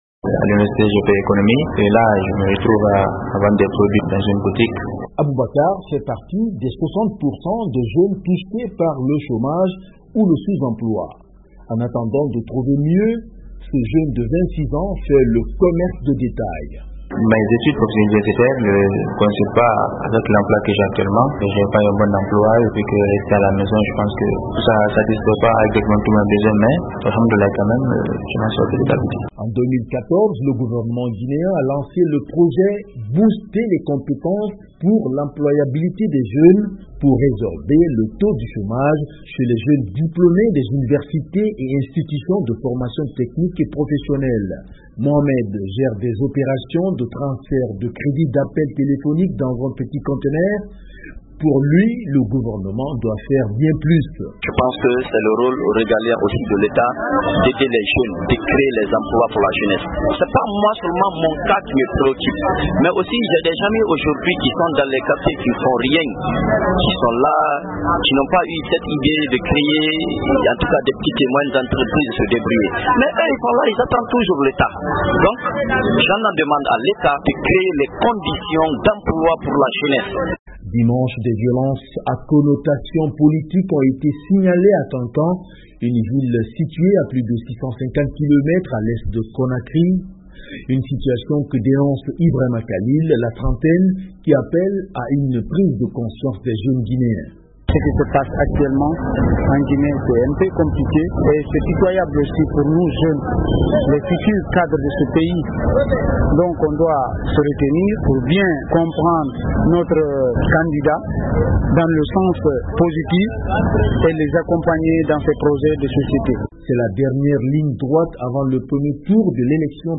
Leur trouver des emplois sera l'un des chantiers prioritaires auquel devra faire face le futur président. A trois jour de la fin de la campagne présidentielle , notre correspondant est allé à la rencontre de certains jeunes à Conakry.